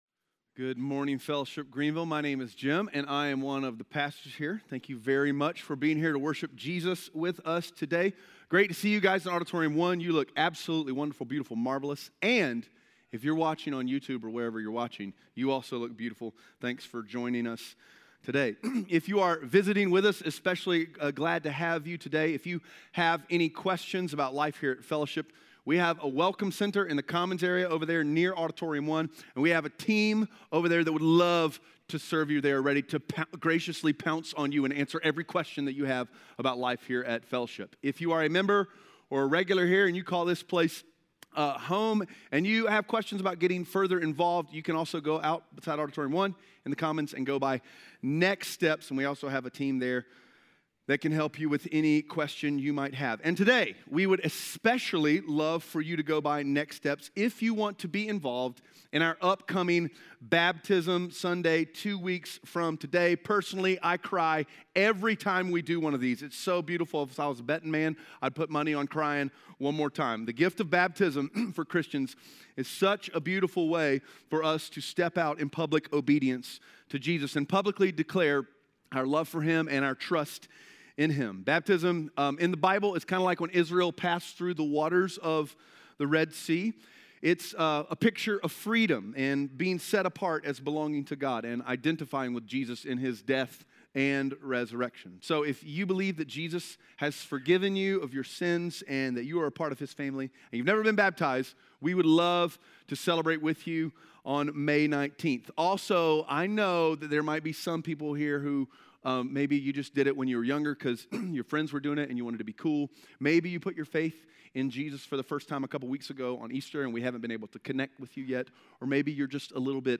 2 Peter 2:1-22 Audio Sermon Notes (PDF) Ask a Question “Fake News” might be one of the only mantras of our day that people agree with.